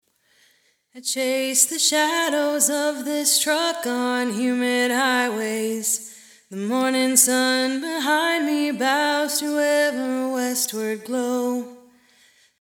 Now, with the same reverb but with an added 40 ms pre-delay the reverb doesn’t get quite so much in the way.
The reverb’s a bit loud but I think you can hear the difference and the point I’m making.
vocalpredelay.mp3